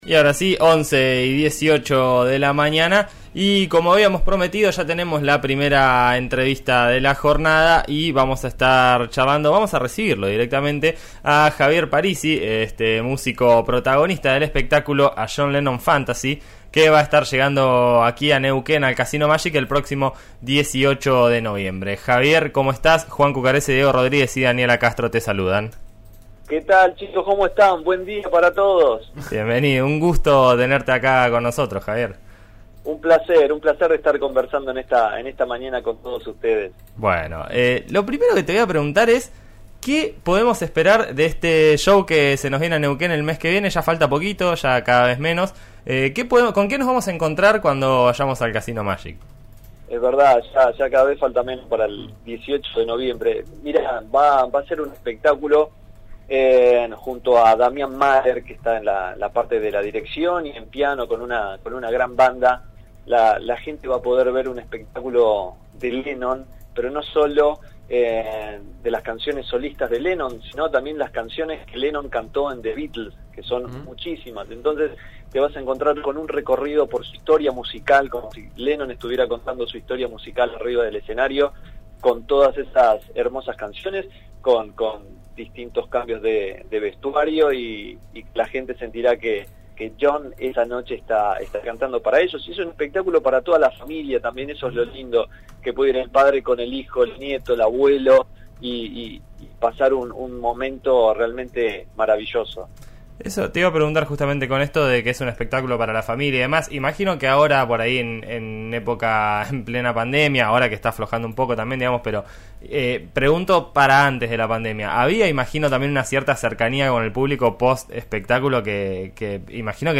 En eso estamos de RN Radio 89.3. La entrevista completa: